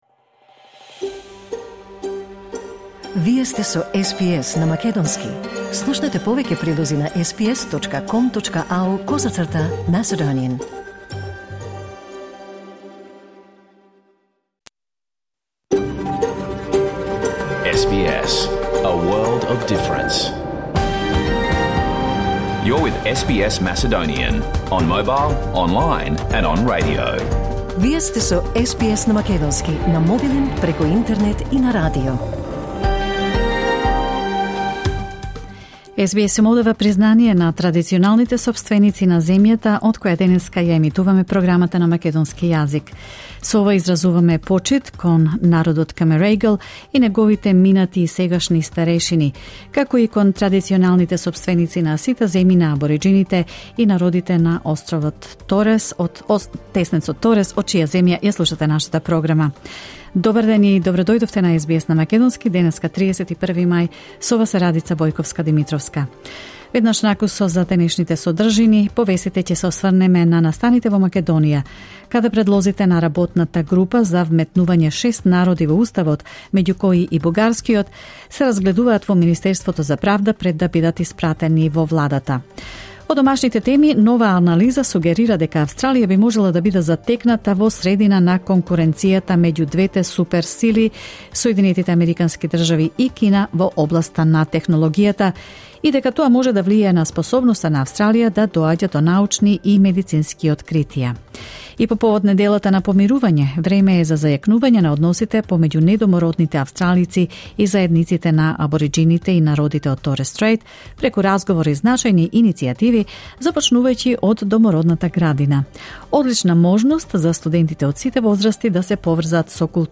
SBS Macedonian Live on Air 31 May 2023